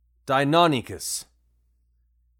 deinonychus.mp3